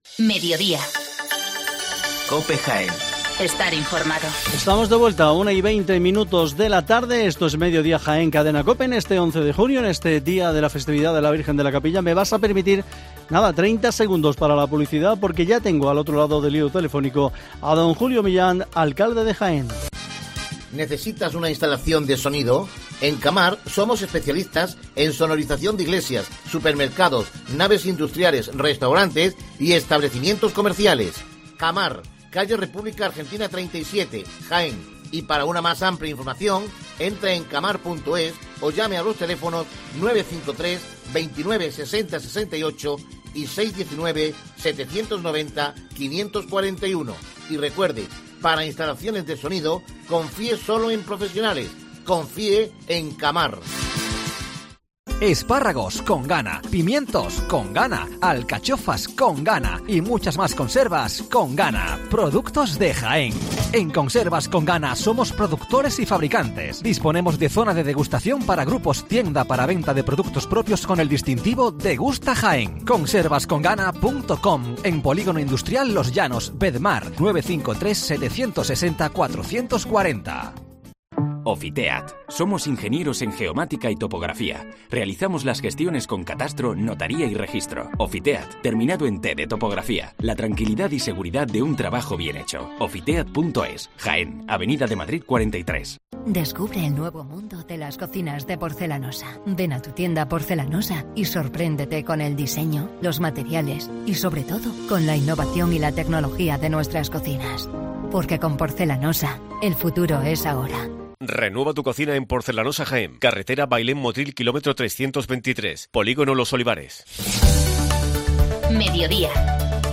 Hoy en COPE hemos charlado con Julio Millán, alcalde de Jaén
ENTREVISTA